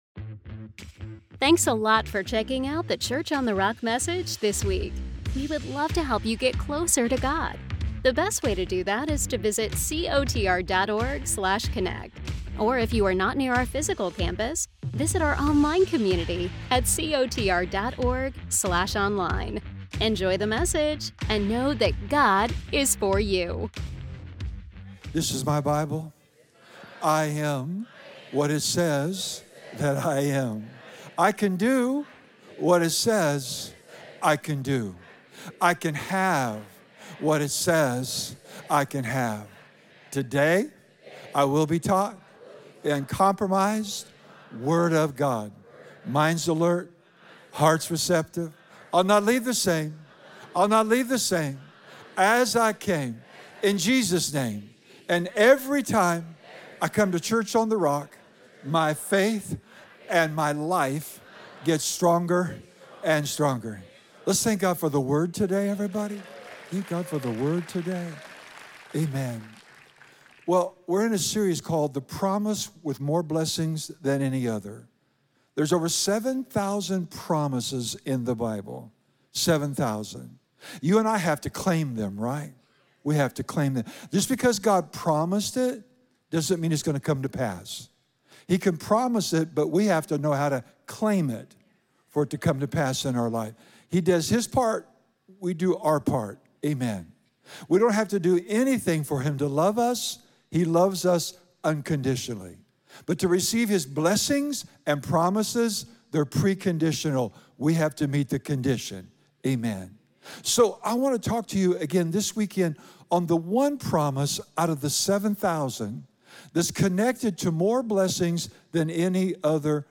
In this powerful message from our series The Promise: With More Blessings Than Any Other, Pastor teaches on the fear of the Lord.